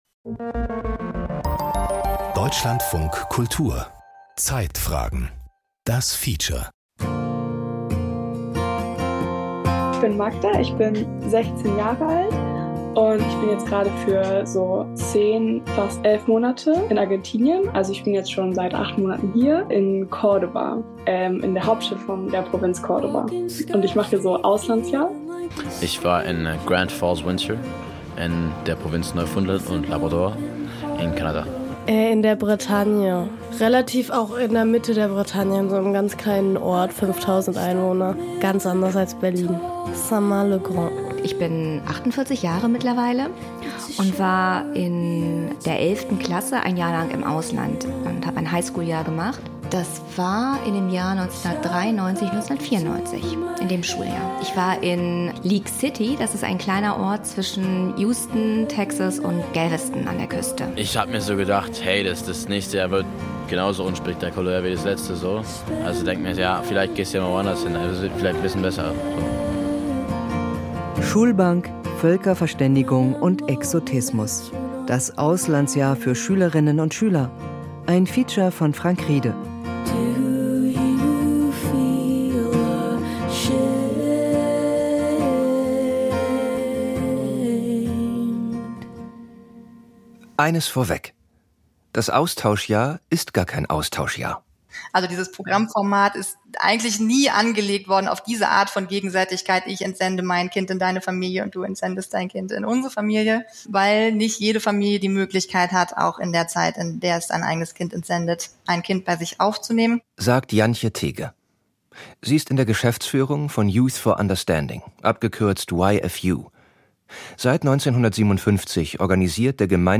Feature Podcast abonnieren Podcast hören Podcast Zeitfragen-Feature Unsere Welt ist komplex, die Informationsflut überwältigend.